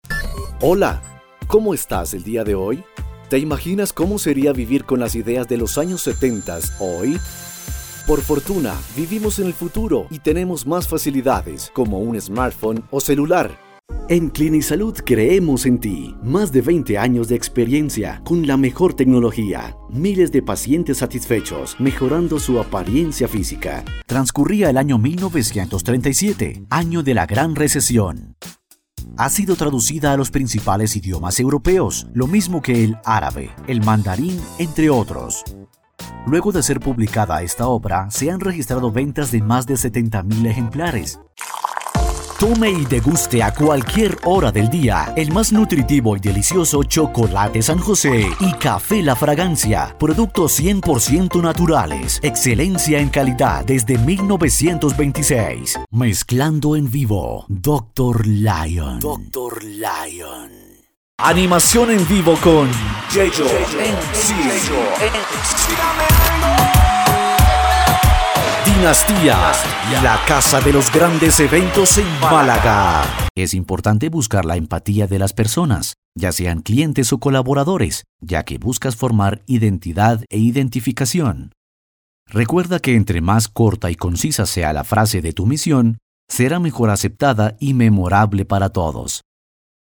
Voiceover (Colombia),
Sprechprobe: eLearning (Muttersprache):
Sprechprobe: Werbung (Muttersprache):